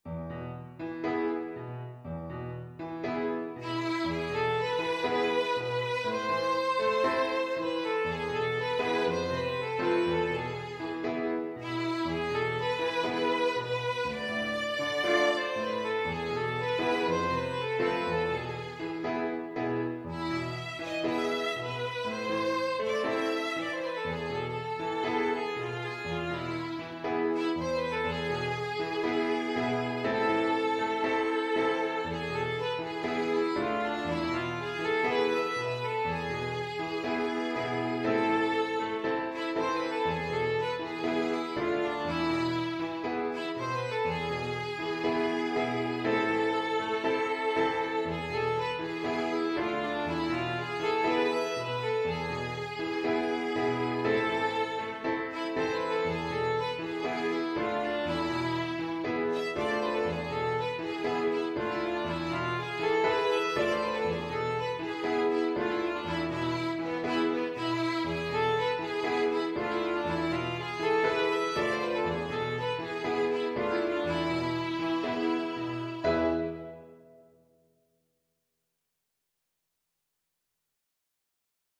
Violin
A minor (Sounding Pitch) (View more A minor Music for Violin )
4/4 (View more 4/4 Music)
Allegro moderato =120 (View more music marked Allegro)
Traditional (View more Traditional Violin Music)
araber_tantz_VLN.mp3